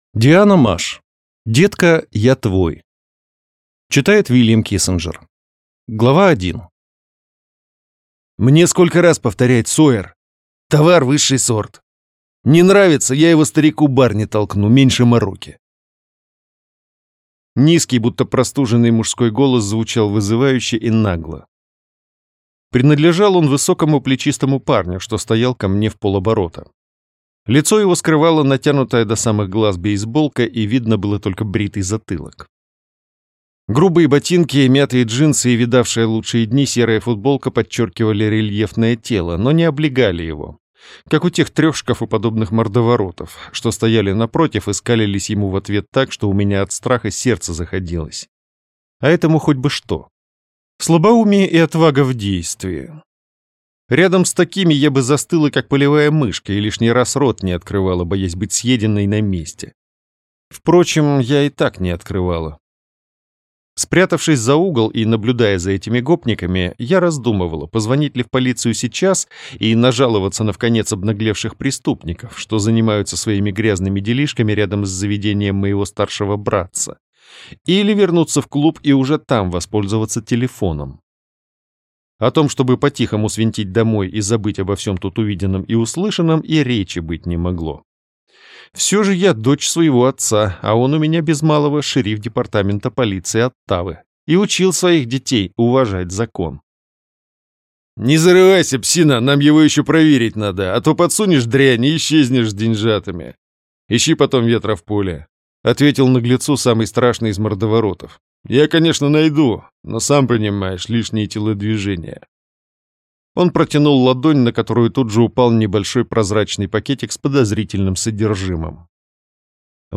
Аудиокнига Детка, я твой!
Прослушать и бесплатно скачать фрагмент аудиокниги